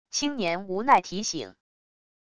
青年无奈提醒wav音频